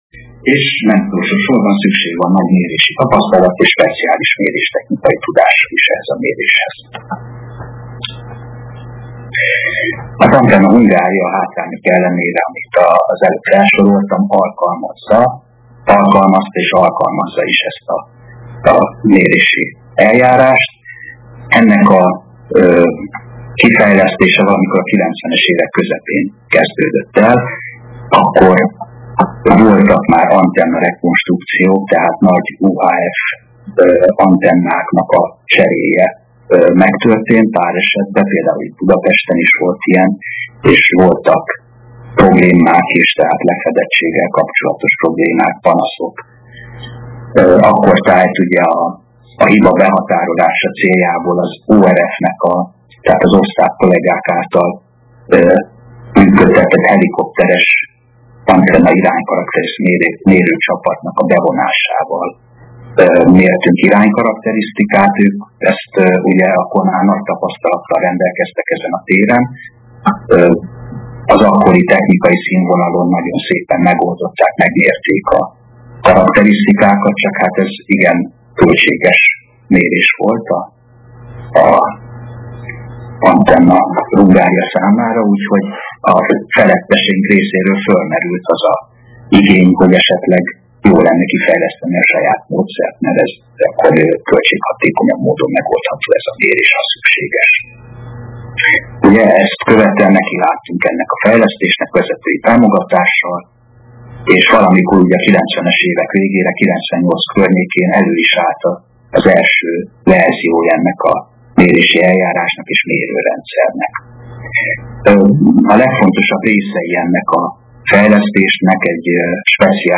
A Vételtechnikai és a Kábeltelevíziós Szakosztály valamint a Médiaklub meghívja az érdeklődőket az alábbi érdekes és aktuális előadásra